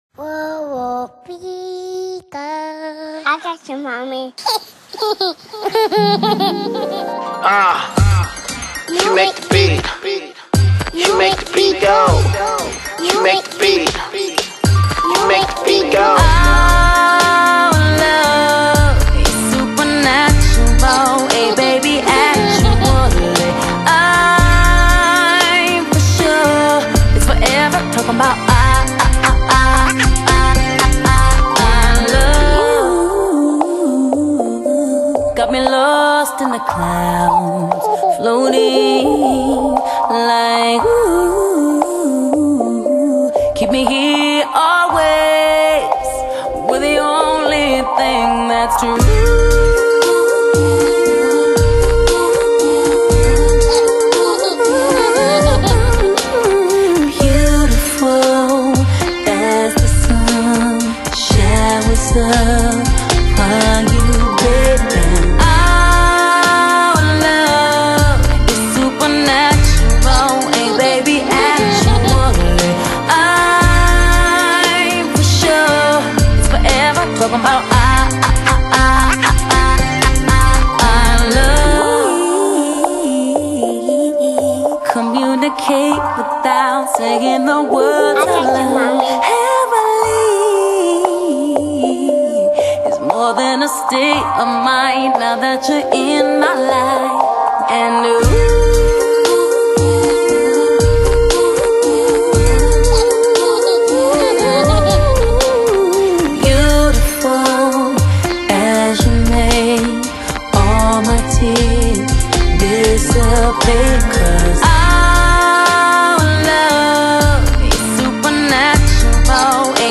美國著名女歌手